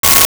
Sci Fi Beep 02
Sci Fi Beep 02.wav